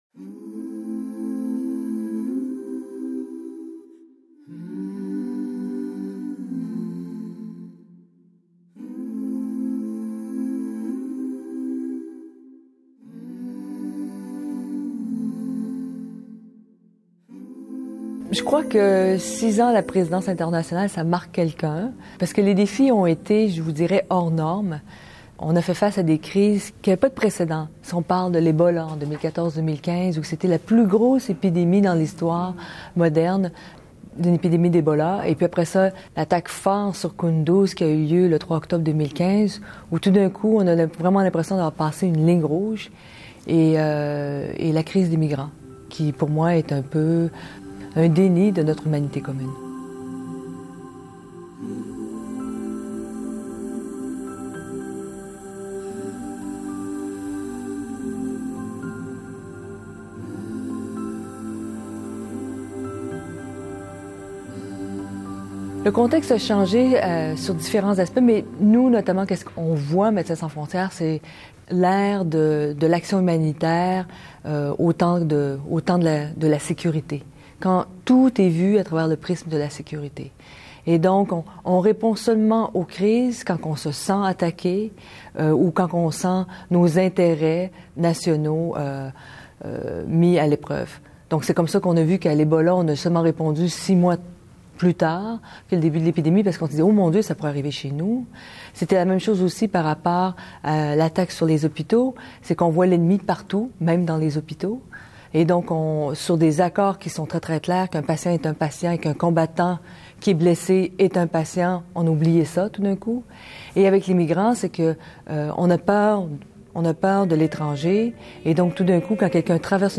Céline Galipeau l’a interviewée au Téléjournal d’ICI TÉLÉ.
En voici une adaptation audio, suivie du segment d’origine de l’entrevue.